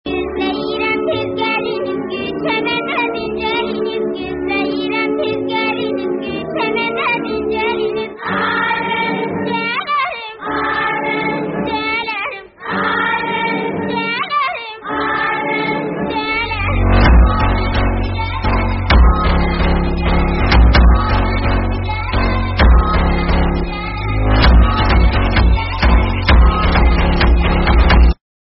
Azerbaijani version